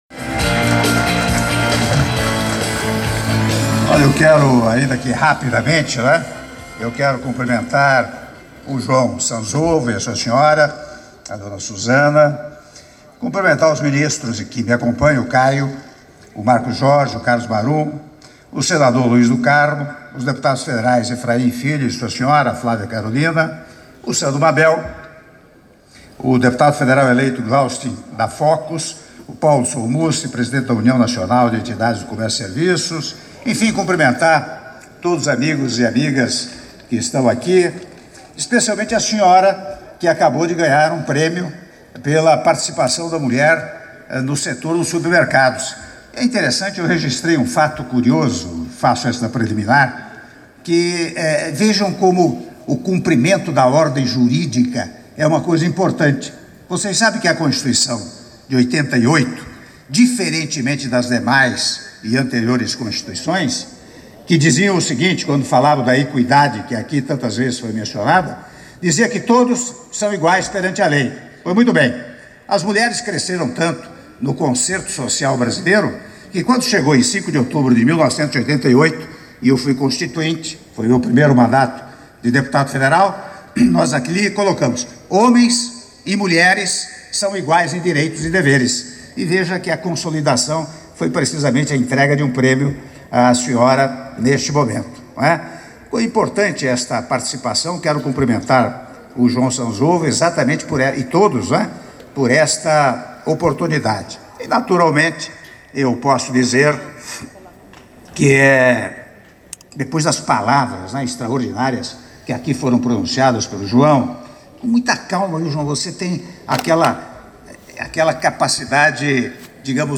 Áudio do discurso do Presidente da República, Michel Temer, durante jantar especial em comemoração aos 50 anos de fundação da Associação Brasileira de Supermercados - ABRAS e ao Dia Nacional do Supermercado - São Paulo/SP (08min17s)